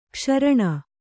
♪ kṣaraṇa